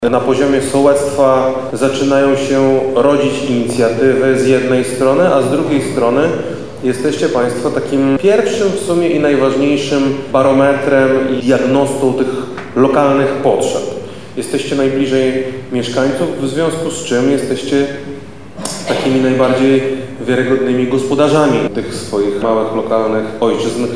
Rola sołtysa jest kluczowa – mówi Krzysztof Komorski, wojewoda Lubelski